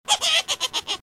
Play, download and share laughing witch original sound button!!!!
witch-laugh.mp3